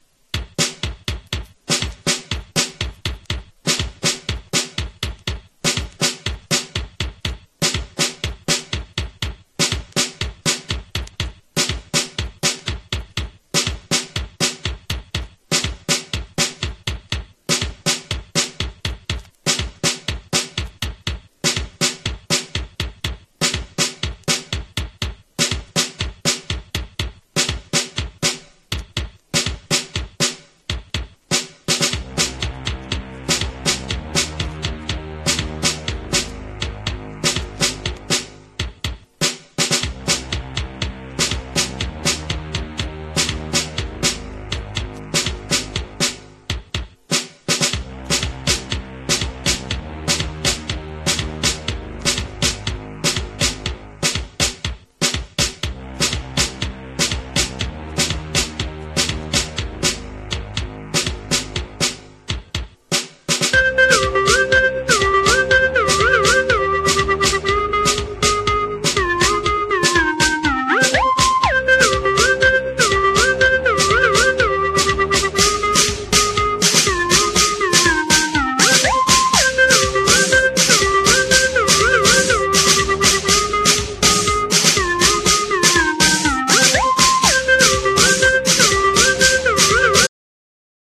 ORIGINAL MIX